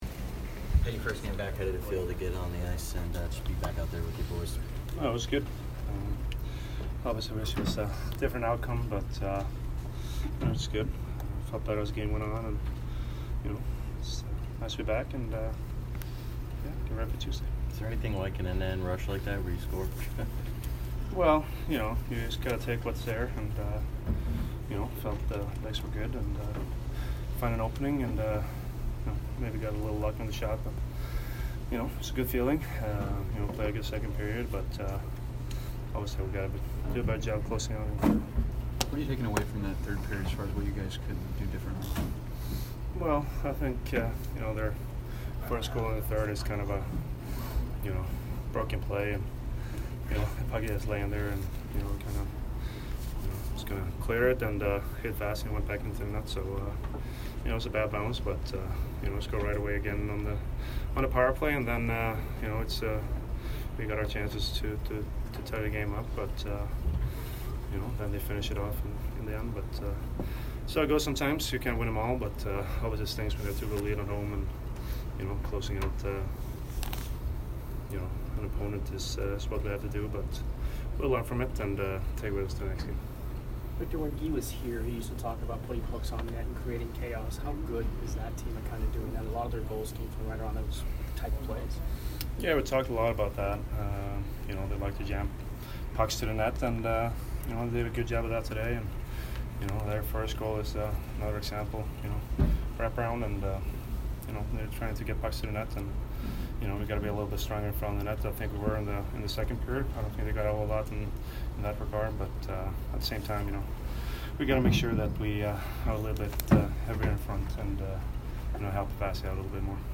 Victor Hedman post-game 11/10